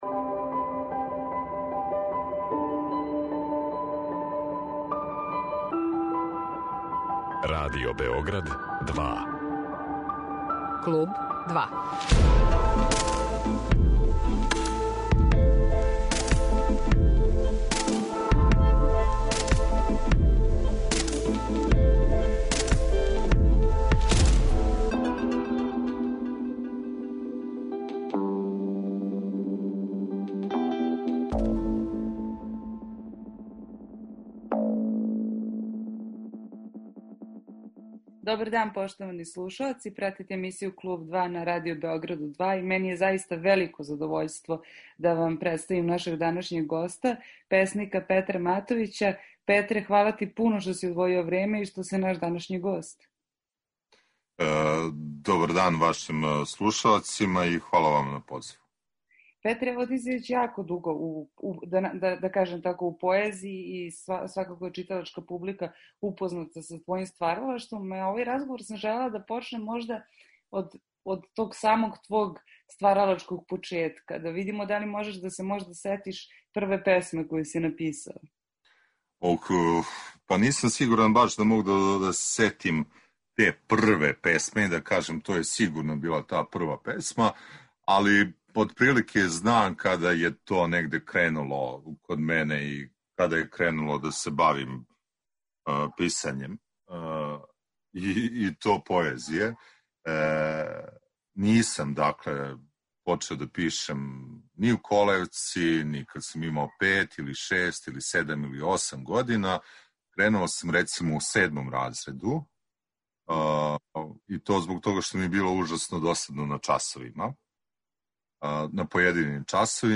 Са њим разговарамо о почецима песничког стваралаштва, о инспирацији и о томе на чему тренутно ради.